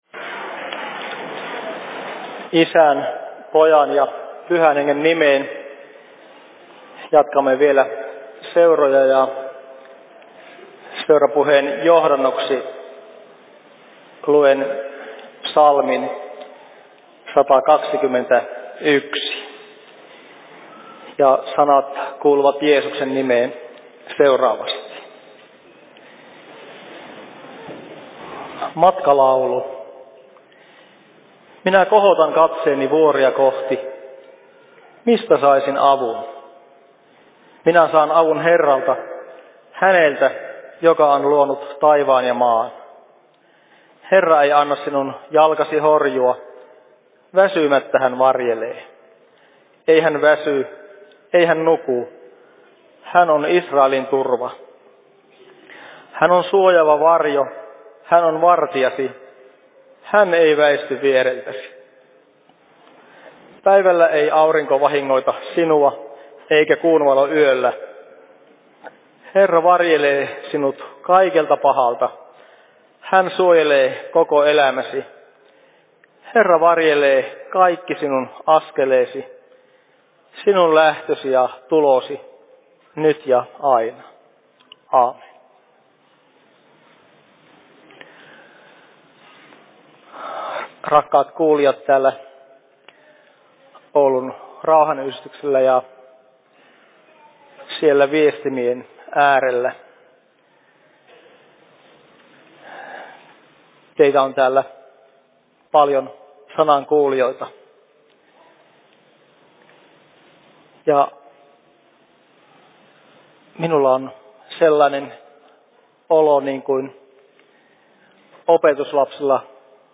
Seurapuhe Oulun RY:llä 16.10.2022 18.27
Paikka: Rauhanyhdistys Oulu